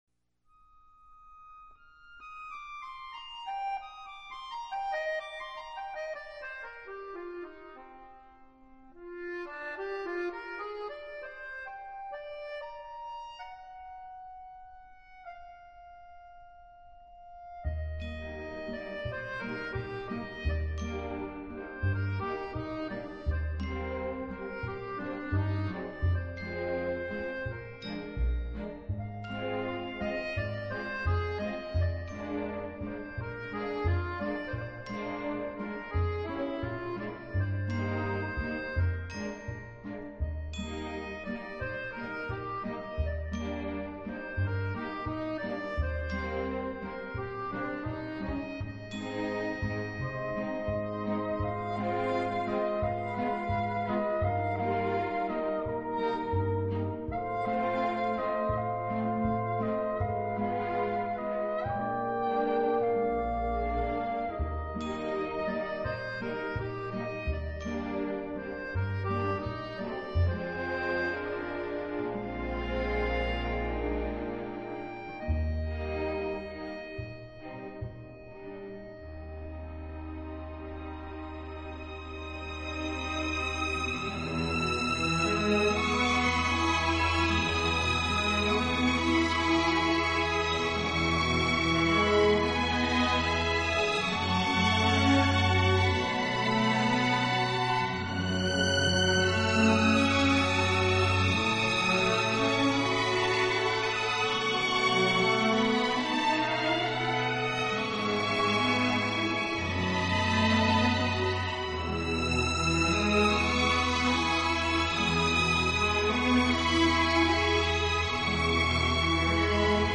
舒展，旋律优美、动听，音响华丽丰满。